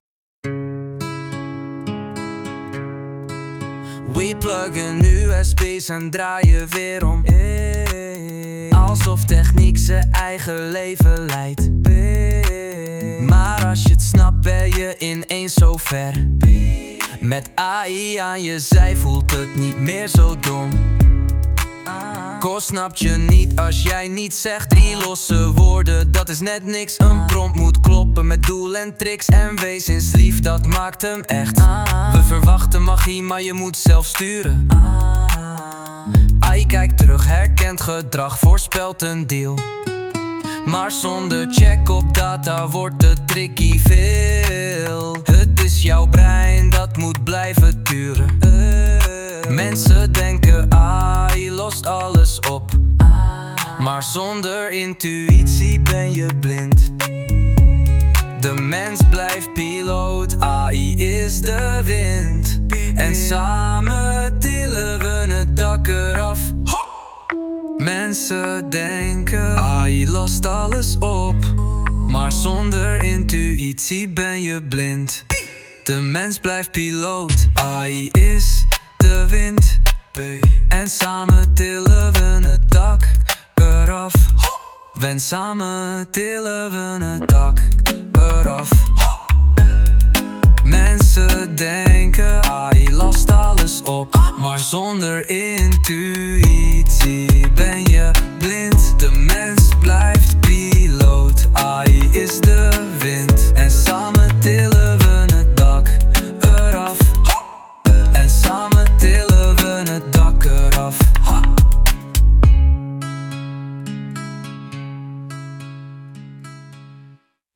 Dit lied is volledig met AI gegenereerd. De teksten zijn afkomstig van de interviews van aflevering 1.